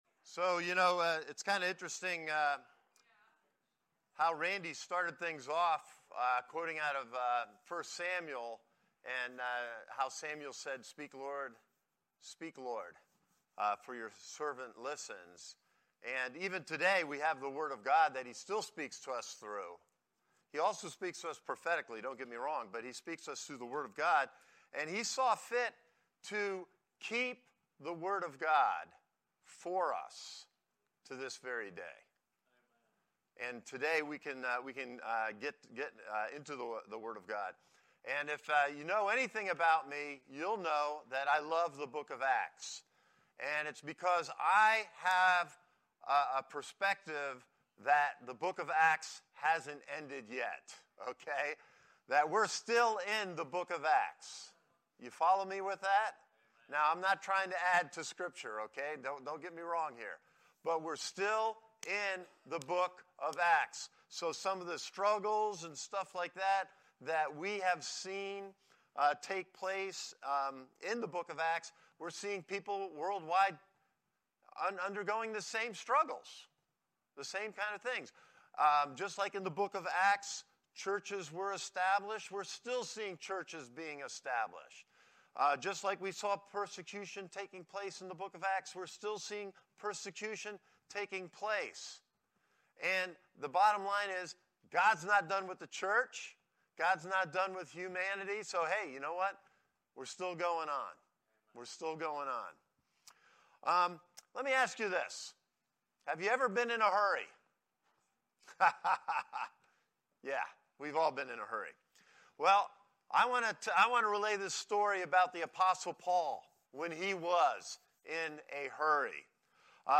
Sermons - Christ Connection Church
This is the main Sunday Service for Christ Connection Church